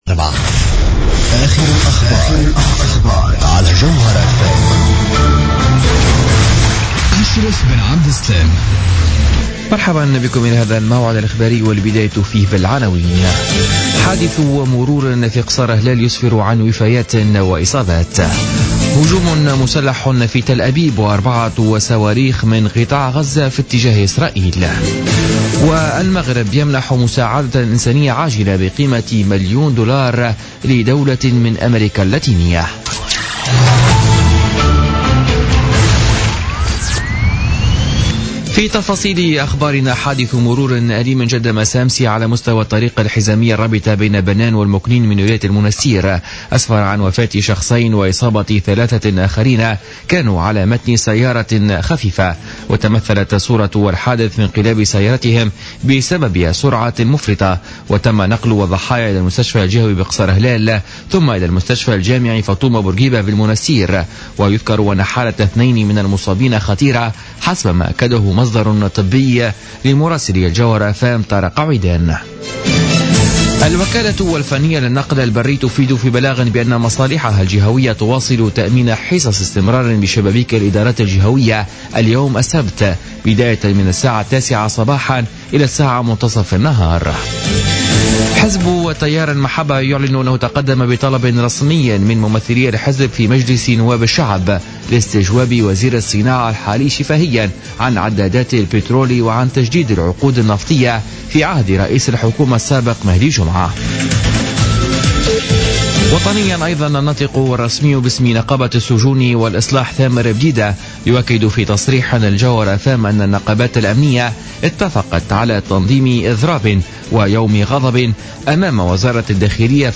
نشرة أخبار منتصف الليل ليوم السبت 2 جانفي 2016